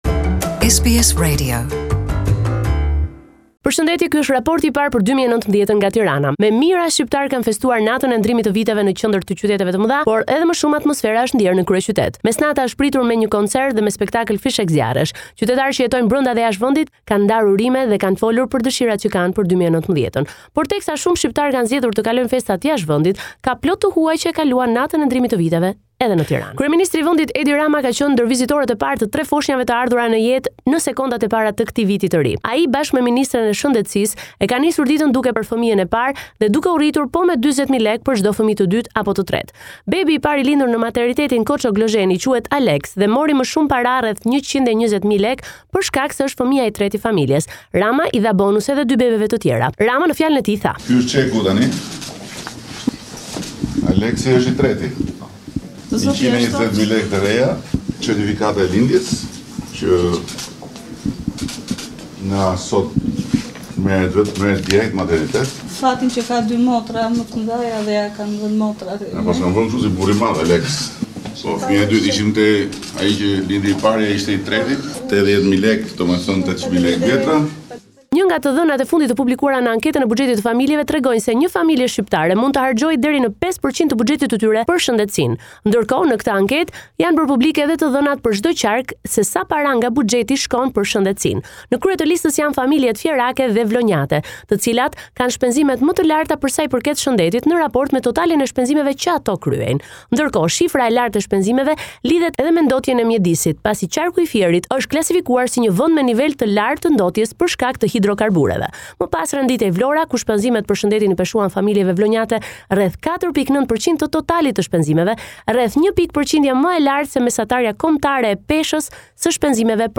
This is a report summarising the latest developments in news and current affairs in Albania